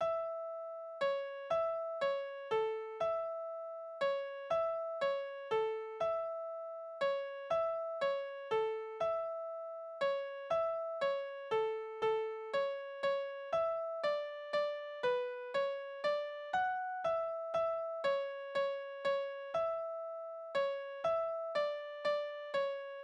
Spielverse
Tonart: A-Dur
Taktart: 3/4
Tonumfang: große Sexte